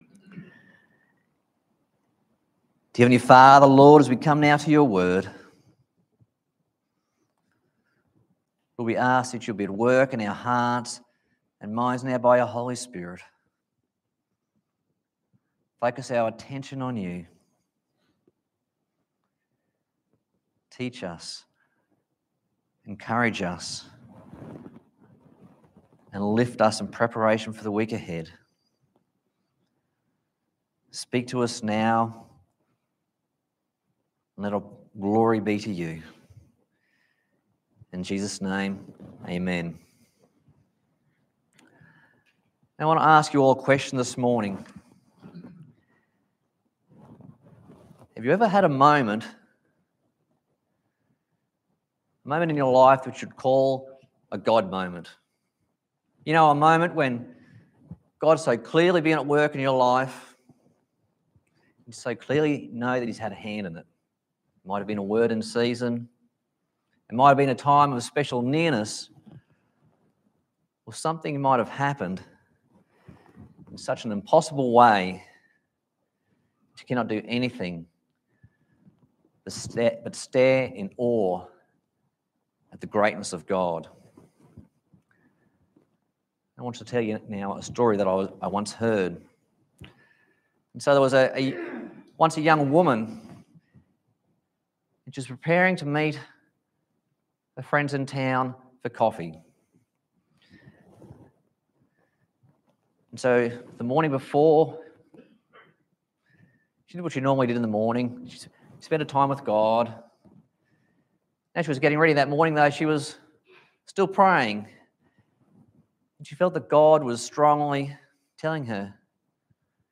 Passage: Luke 7:11-17 Service Type: Sunday Morning